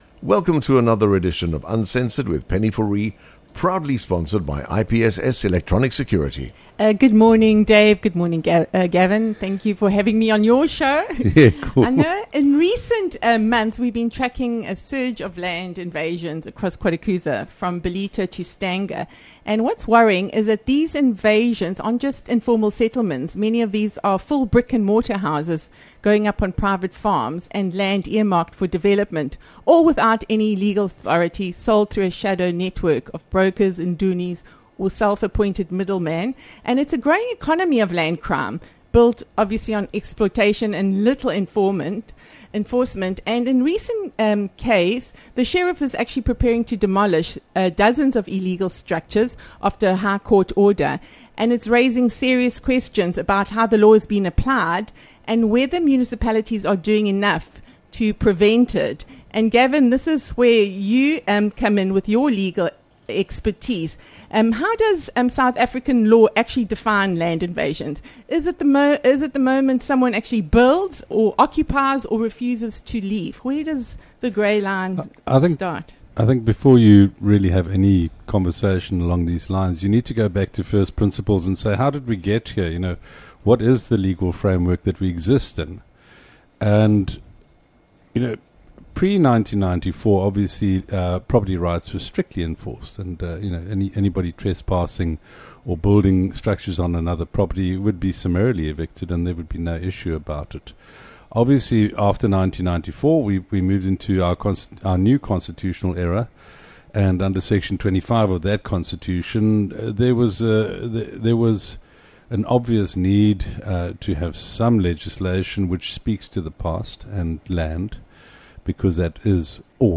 Next week, we take this discussion out of the studio to the ground, where the fault lines are widening.